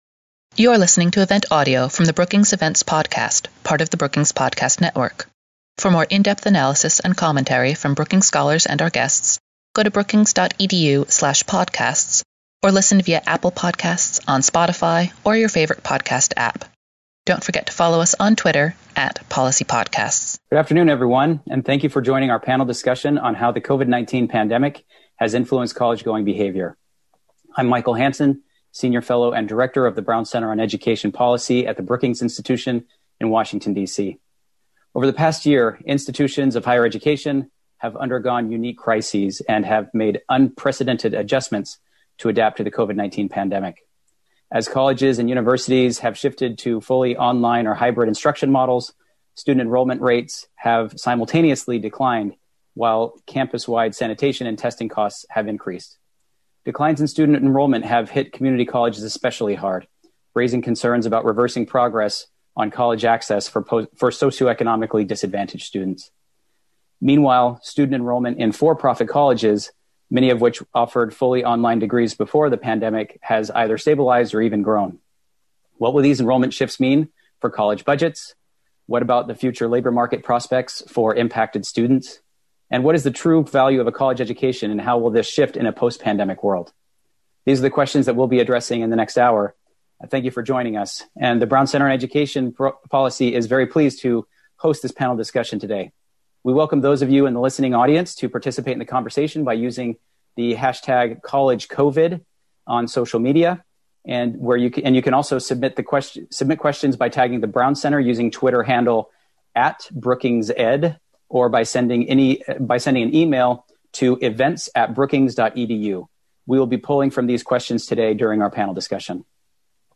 On March 16, Brookings hosted a webinar to discuss how colleges and universities have been affected by coronavirus.